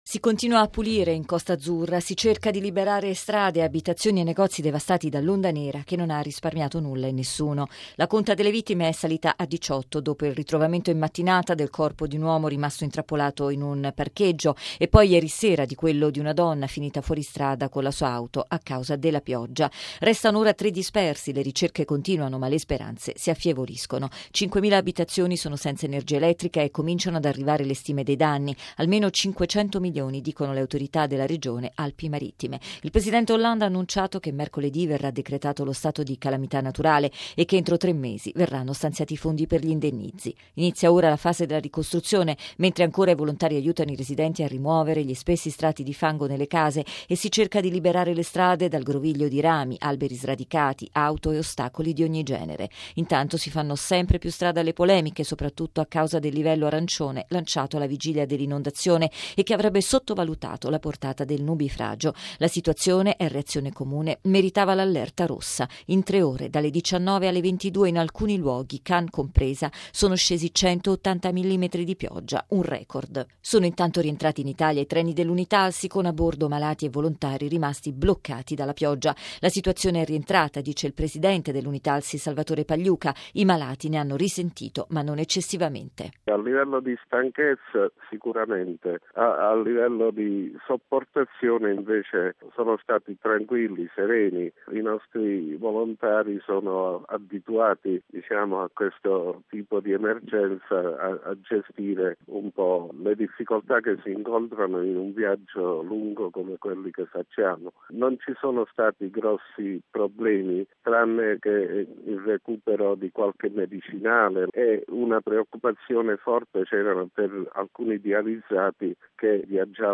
Bollettino Radiogiornale del 05/10/2015